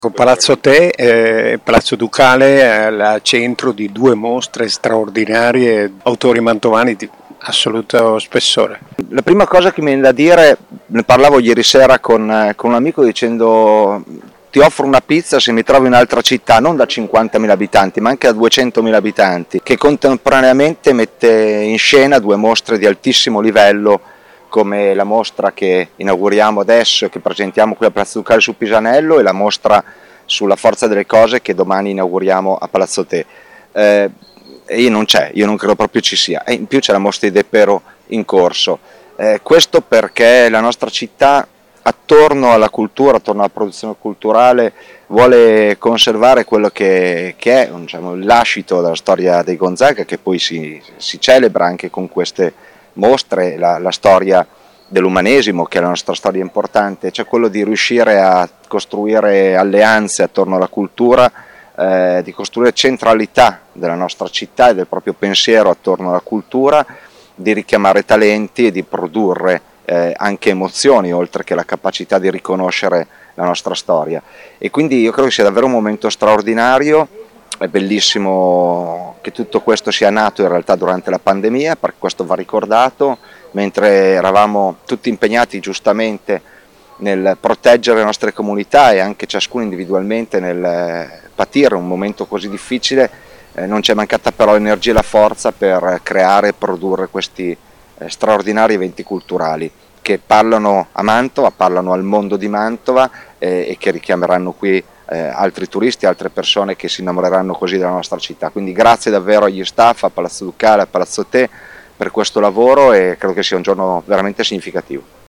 Mattia Palazzi sindaco di Mantova.
Mattia-Palazzi-sindaco-di-Mantova.mp3